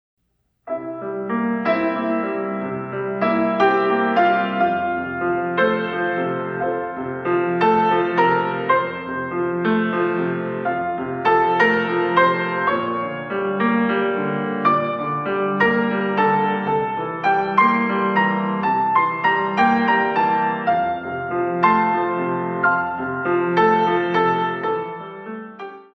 In 3
64 Counts